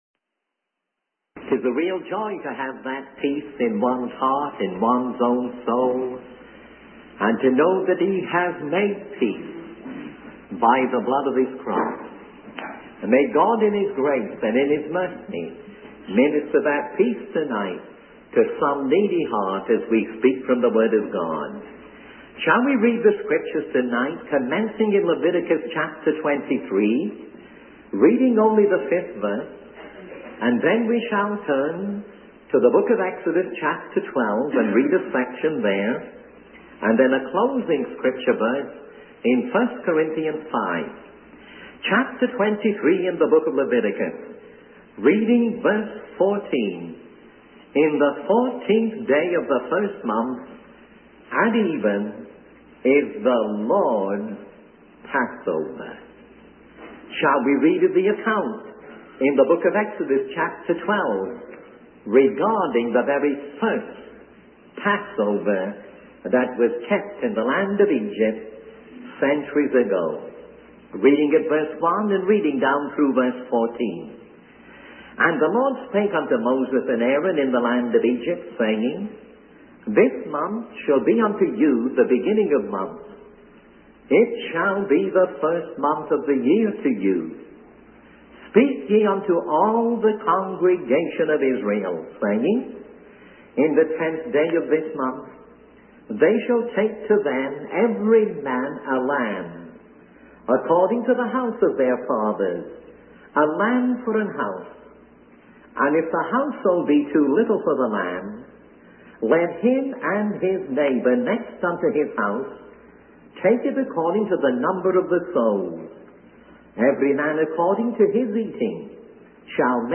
In this sermon, the preacher emphasizes the importance of being washed in the blood of the Lamb, which is the foundation of God's redemption. The sermon encourages believers to be ready to leave the world and to put their trust in Jesus Christ.